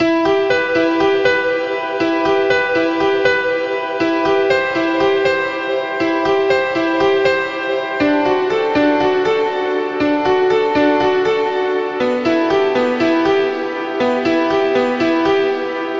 钢琴循环
Tag: 120 bpm Chill Out Loops Piano Loops 1.35 MB wav Key : E